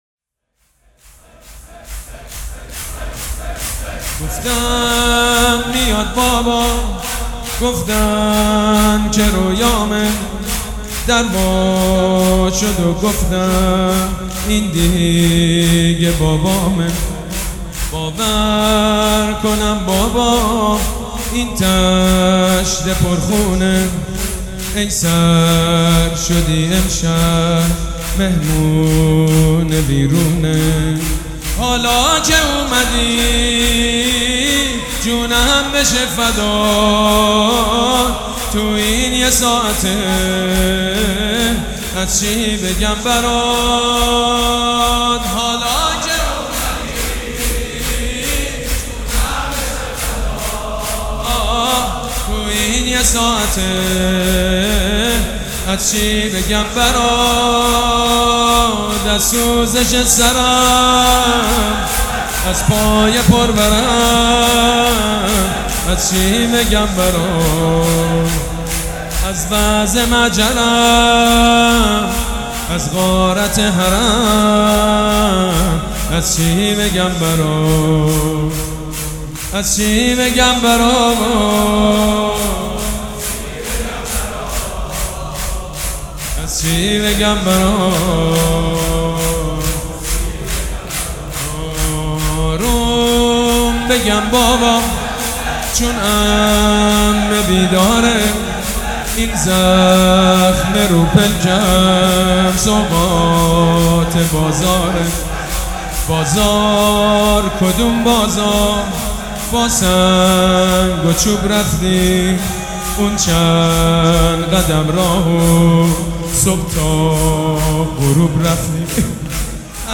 شور
حاج سید مجید بنی فاطمه
مراسم عزاداری شب سوم